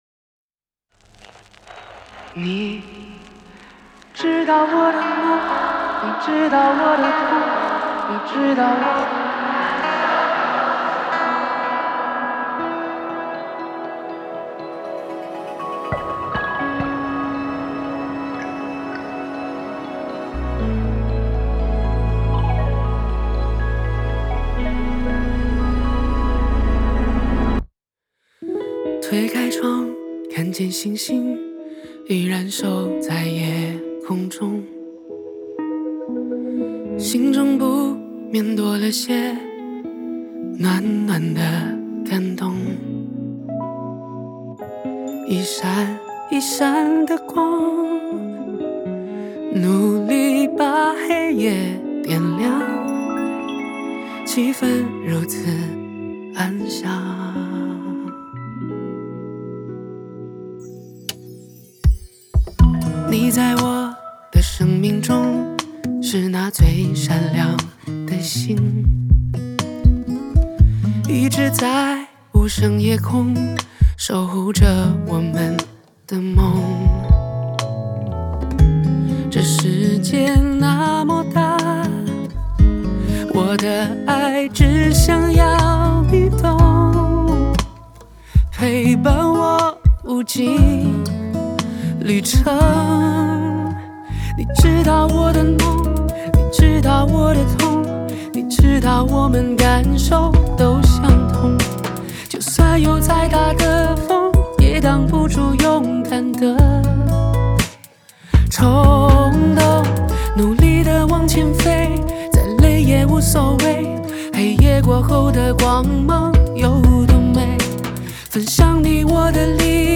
Ps：在线试听为压缩音质节选，体验无损音质请下载完整版
吉他
和声